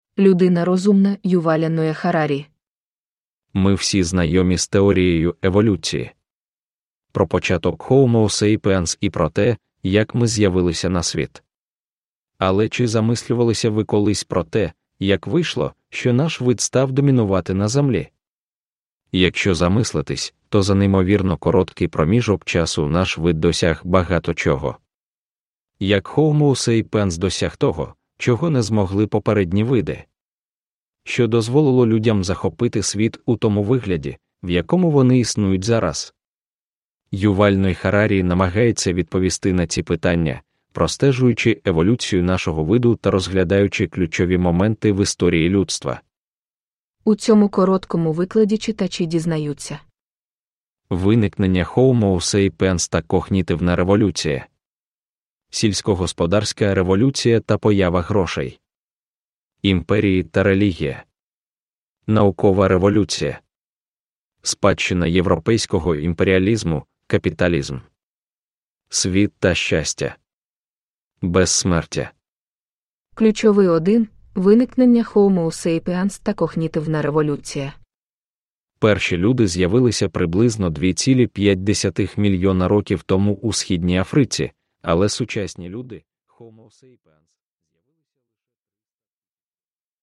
Sapiens – Ljudbok – Laddas ner